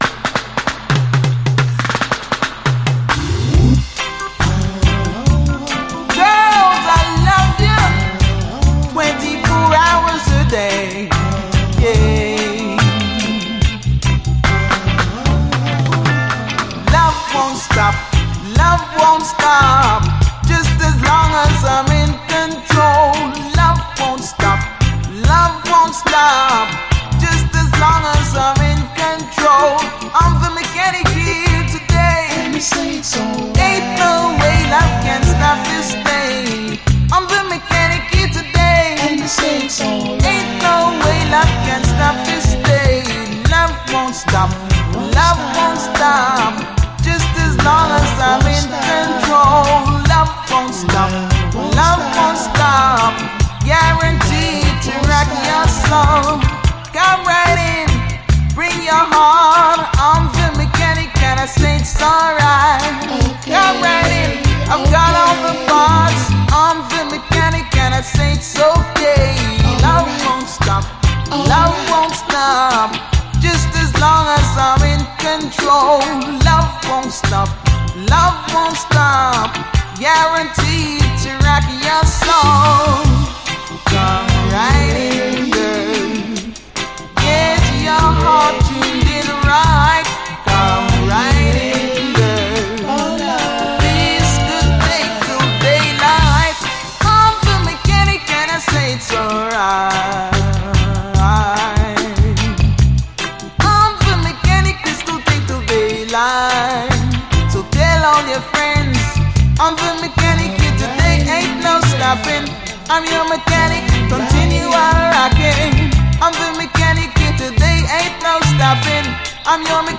REGGAE
UKルーツ印のソフトでまろやかなコーラス・ハーモニーがやはり素晴らしいです。